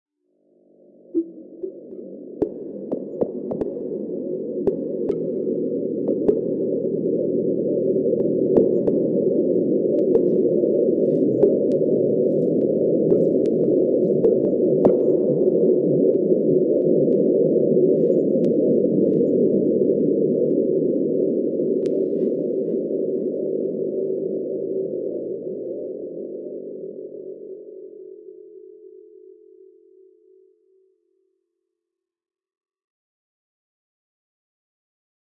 描述：电子场在低密度范围内有各种滑音，和谐音效果，此外还撒上了散布在场内的短音
Tag: 电子 字段 层传播